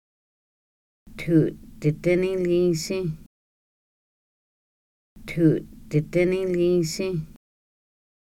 Individual audio recordings of Kaska words and phrases about the weather. This sixth subset of the original Weather Deck focuses on floods and fires.